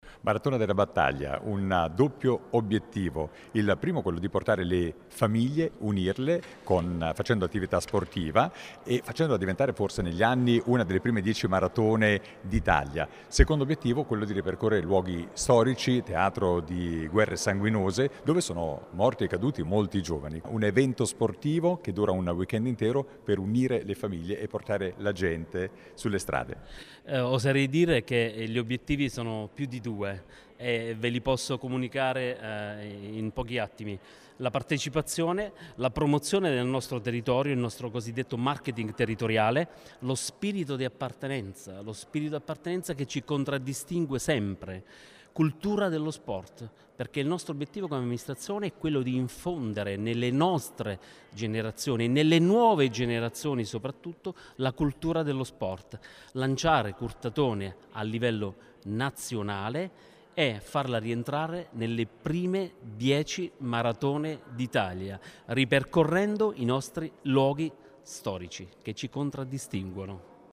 L’evento sportivo, di carattere nazionale, è il primo appuntamento di questo tipo che vede coinvolto il territorio mantovano. ce ne ha parlato il consigliere comunale di Curtatone con delega allo sport, Matteo Totaro: